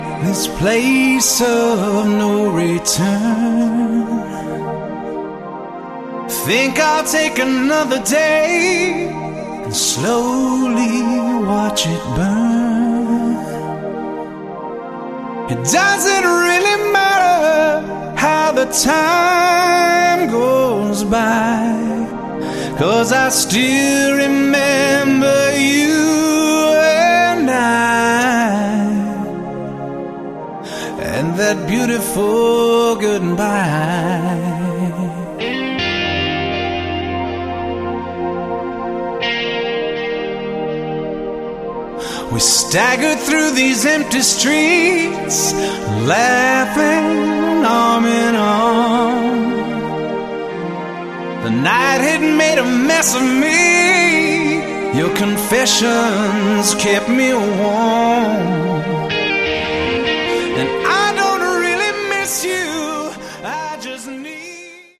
Category: Hard Rock
vocals, acoustic guitars, electric guitar
bass, keyboards